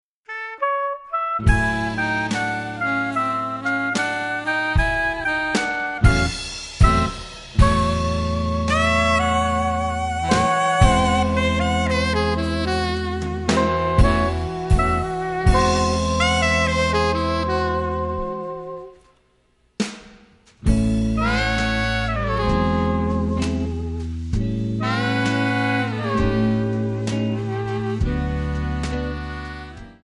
Backing track files: Jazz/Big Band (222)